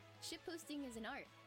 Play, download and share Shitposting – An art original sound button!!!!
shitposting-seal1.mp3